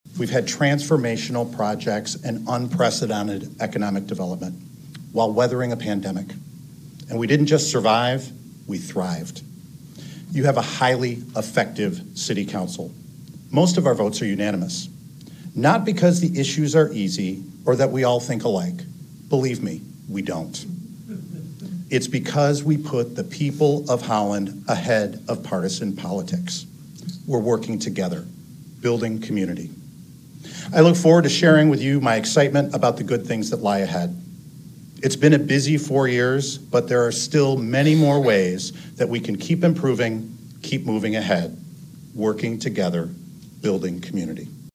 HOLLAND, MI (WHTC-AM/FM, Sept. 19, 2023) – The two men vying to be Mayor of Holland faced each other on Monday night.